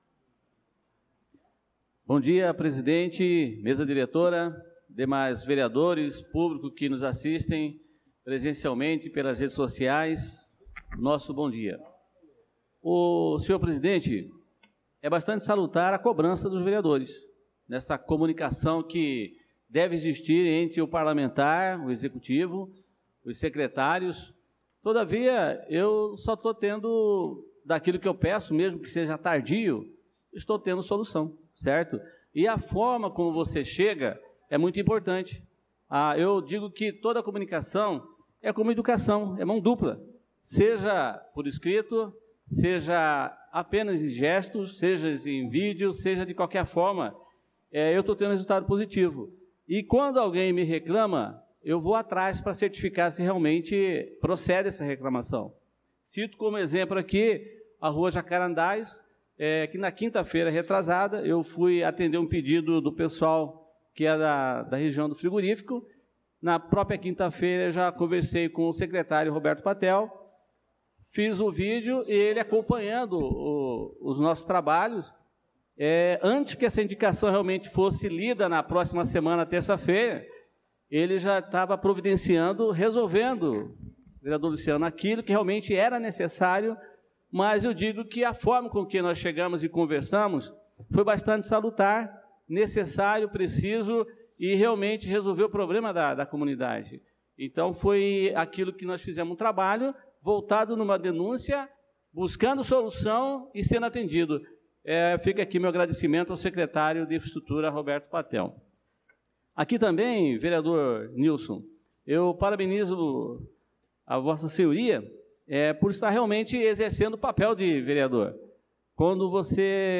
Pronunciamento do vereador Adelson na Sessão Ordinária do dia 25/02/2025